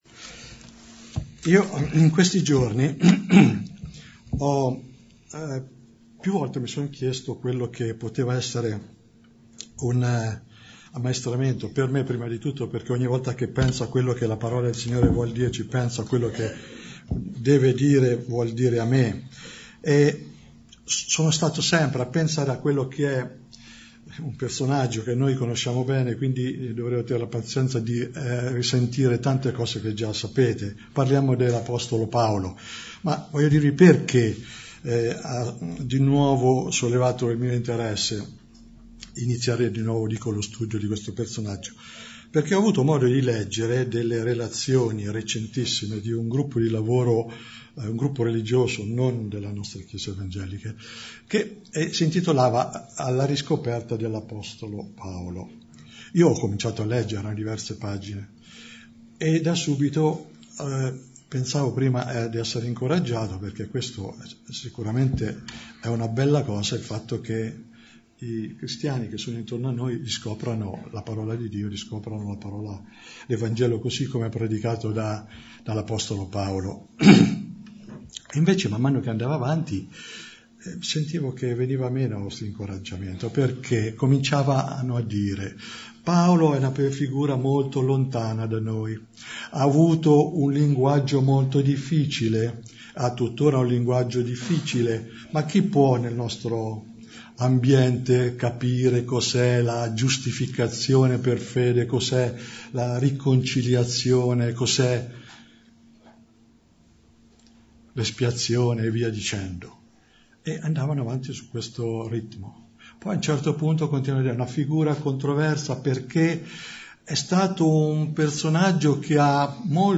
Bible Text: 1 Timoteo-1:12_16 | Preacher